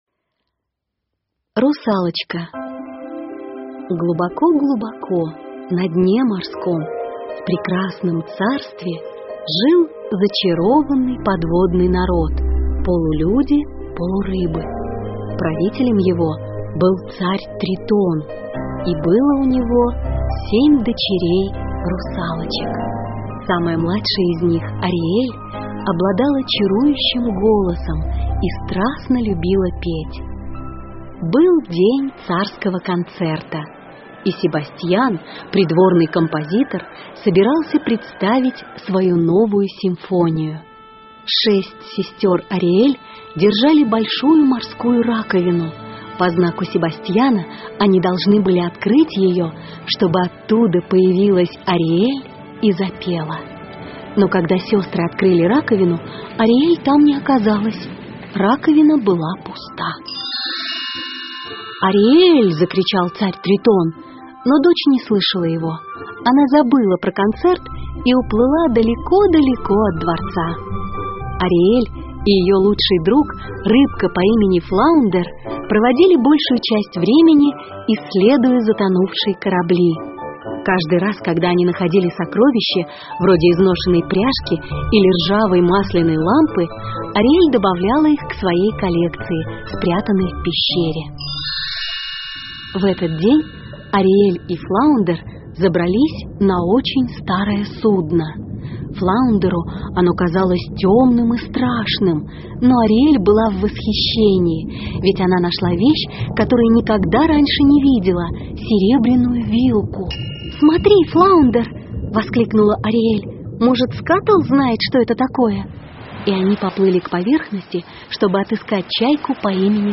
Аудиосказка Русалочка для детей любого возраста в формате mp3 — слушать или скачать бесплатно и без регистрации.